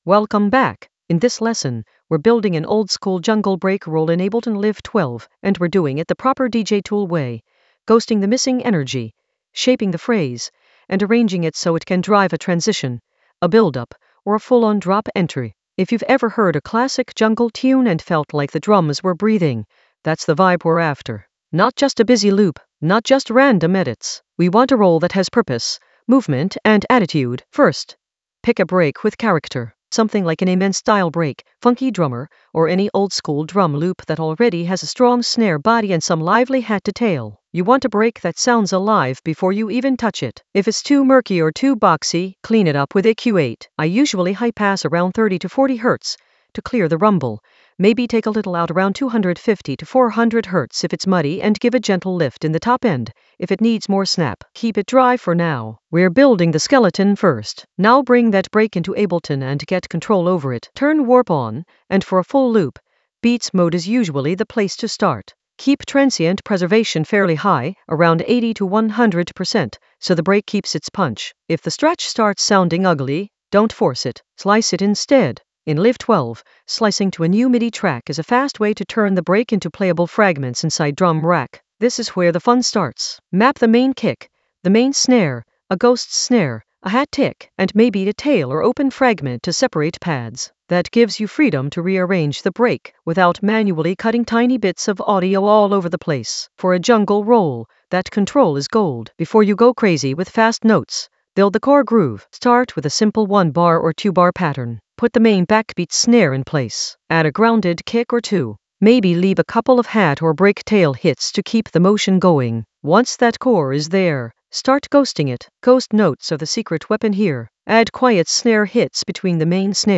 An AI-generated intermediate Ableton lesson focused on Oldskool jungle break roll: ghost and arrange in Ableton Live 12 in the DJ Tools area of drum and bass production.
Narrated lesson audio
The voice track includes the tutorial plus extra teacher commentary.